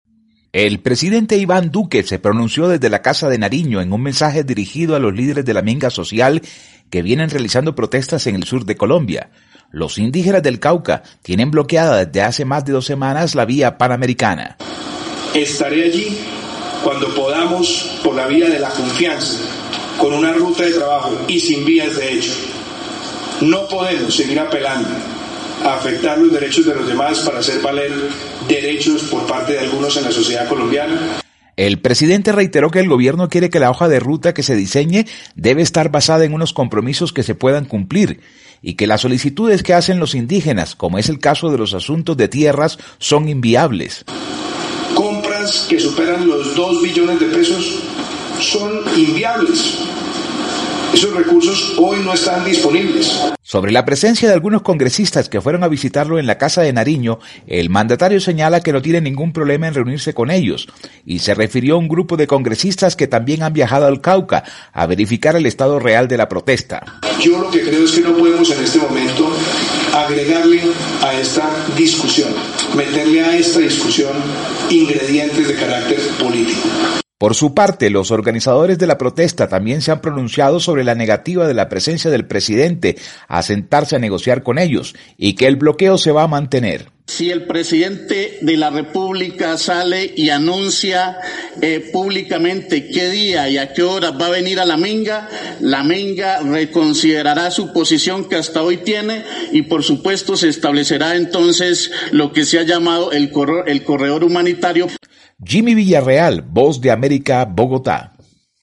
VOA: Informe desde Colombia